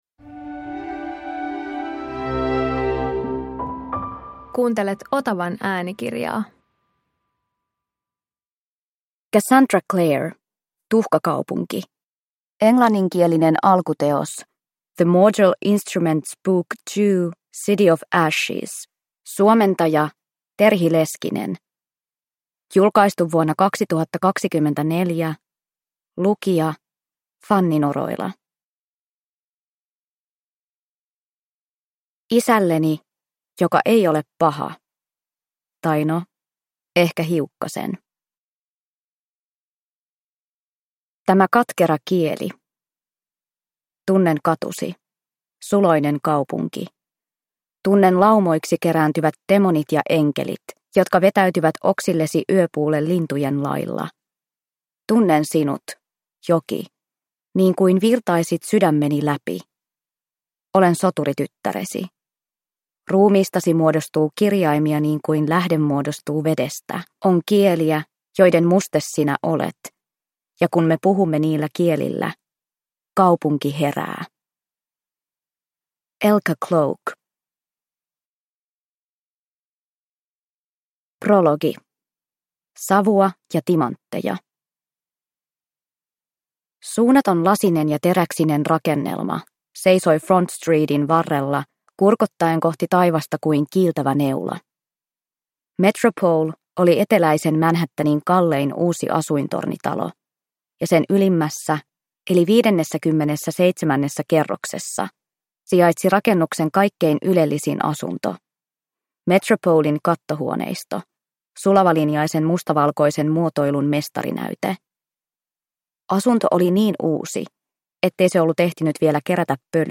Tuhkakaupunki – Ljudbok